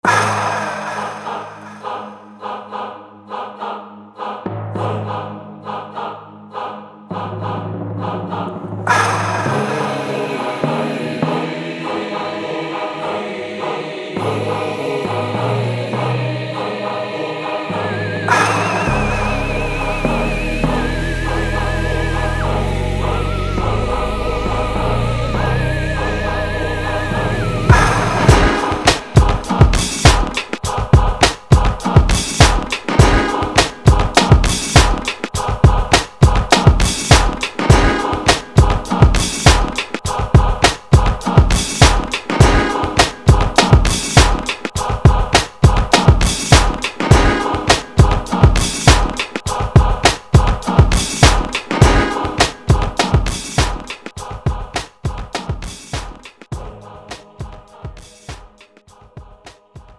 장르 pop 구분 Premium MR